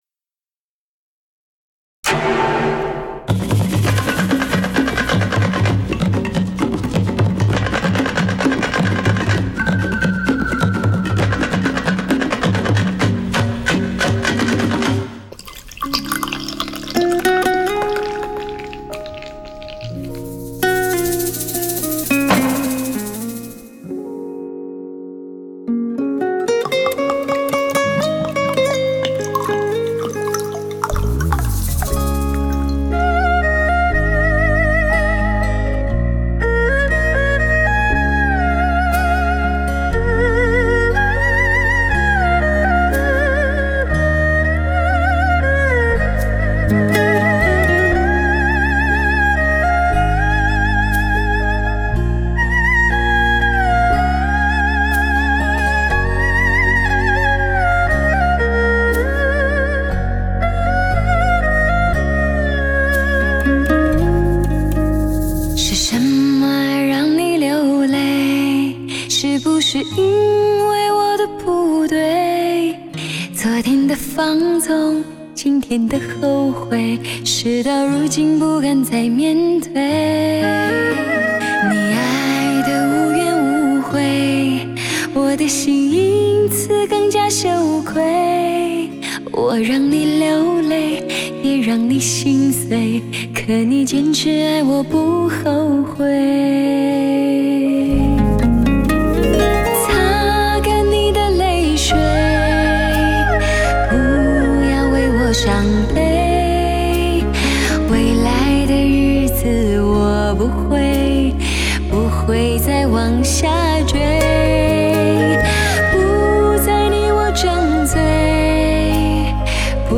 同6个独立声道组成，左前置、前中置、右前置、
保证了音色的清晰度和更宽阔的音域动态范围。
让音乐的现场效果更细腻、逼真、音质更纯正。
6个声道的数码输出，全面超越传统CD（2声道）